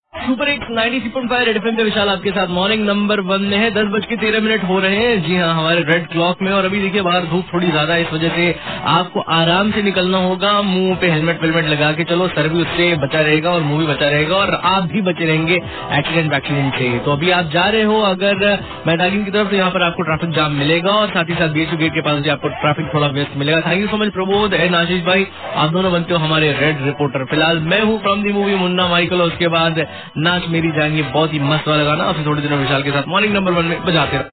Traffic Report